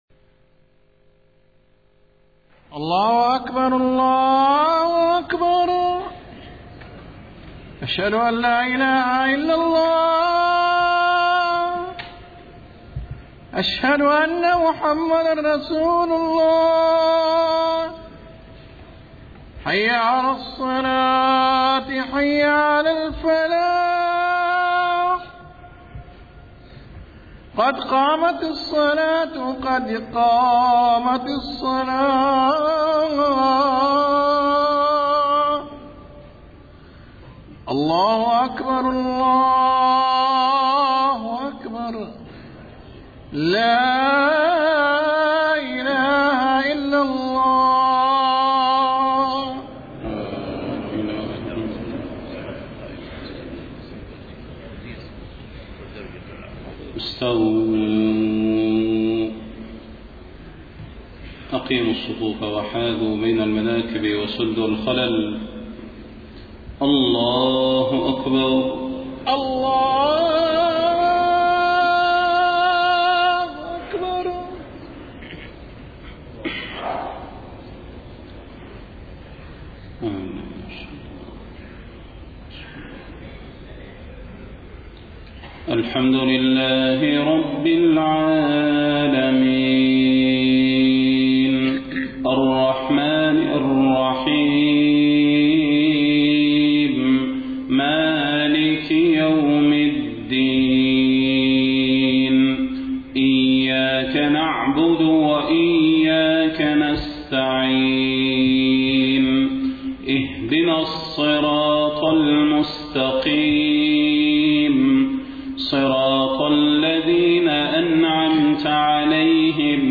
صلاة الفجر 29 صفر 1431هـ فواتح سورة النحل 1-23 > 1431 🕌 > الفروض - تلاوات الحرمين